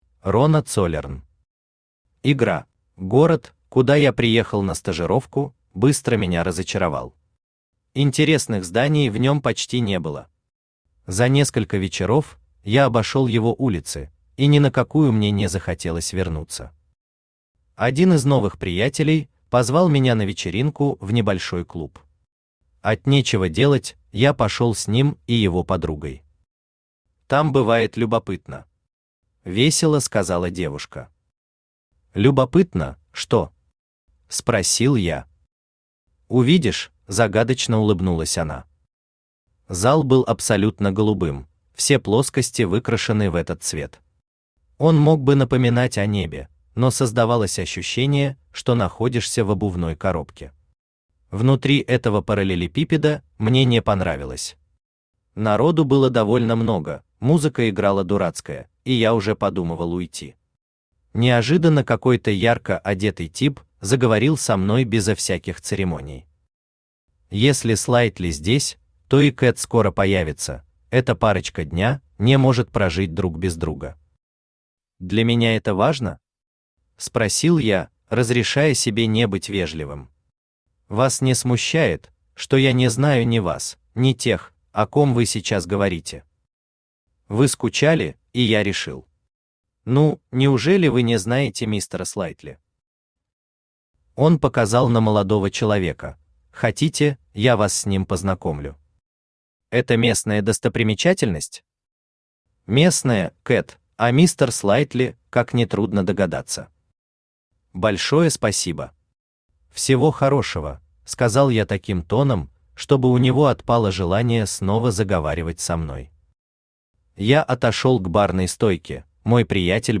Aудиокнига Игра Автор Рона Цоллерн Читает аудиокнигу Авточтец ЛитРес.